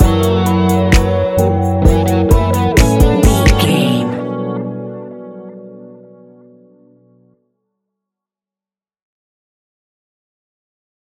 Ionian/Major
B♭
laid back
Lounge
sparse
chilled electronica
ambient
atmospheric
instrumentals